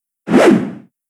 Dash Sound.wav